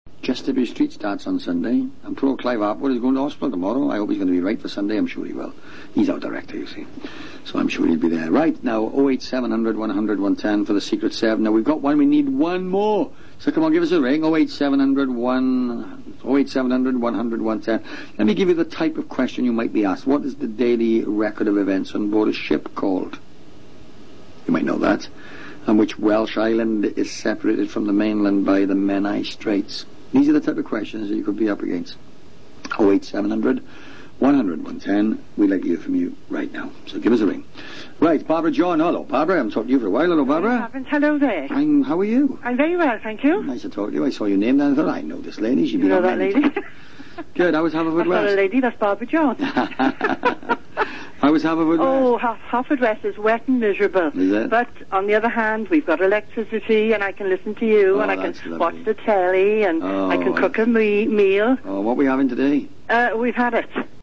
Welsh English
The most salient feature of Welsh English is the intonation which is similar to that of the Welsh language. People from Wales also tend to have a low central vowel in a word like bad [bad] rather than [bæd].